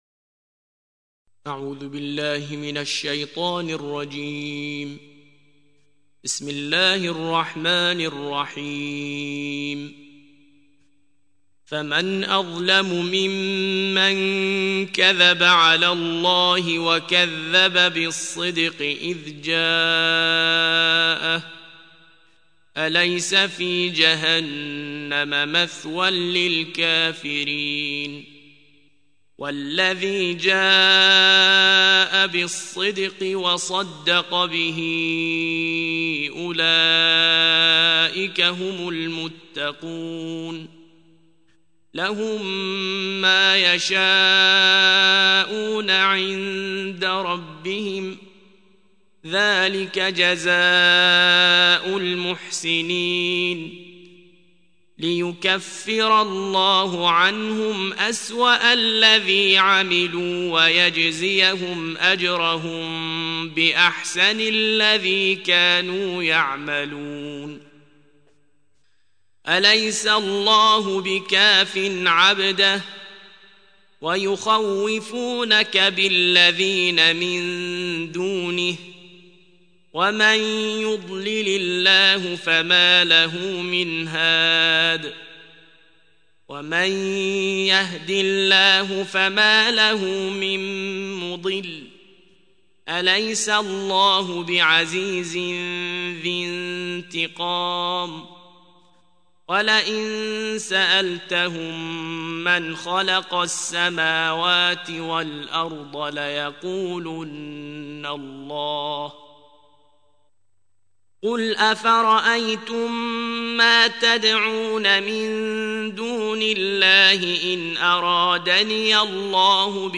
ترتیل جزء بیست‌وچهارم قرآن